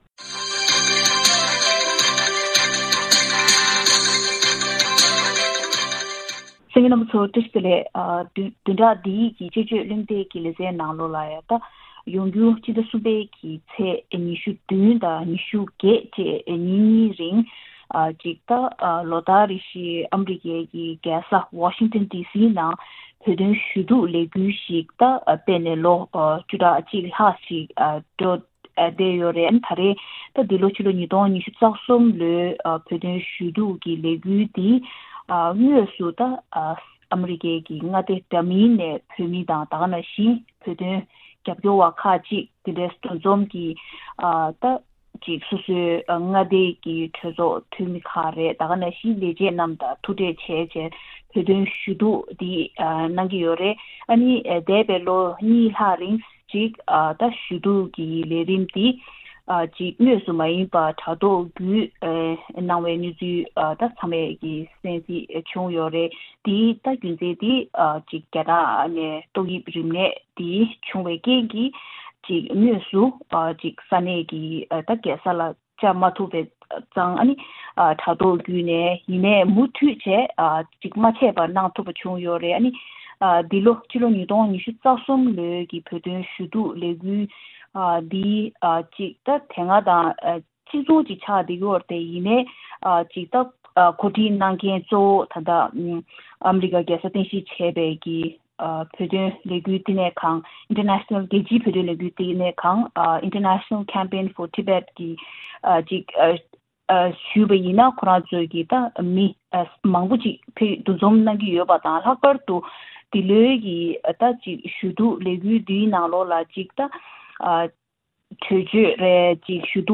དཔྱད་བརྗོད་གླེང་སྟེགས